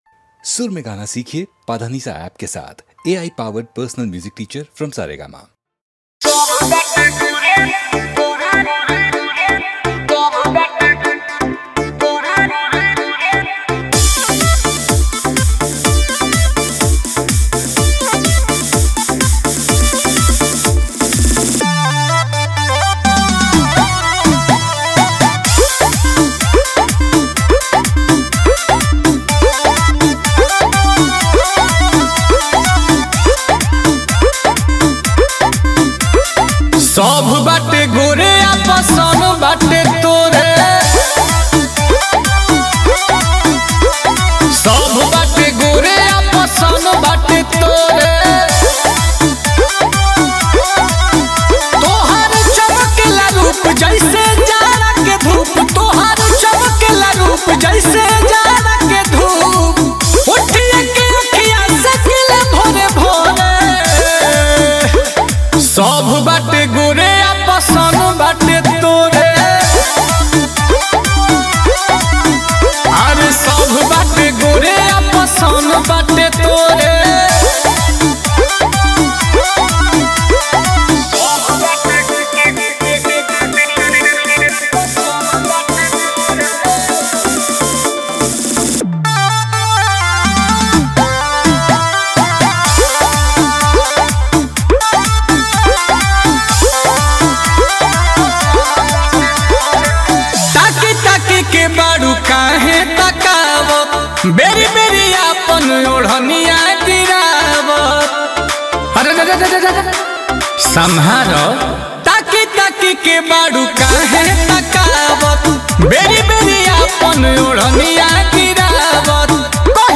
Category Bhojpuri New Mp3 Songs Singer(s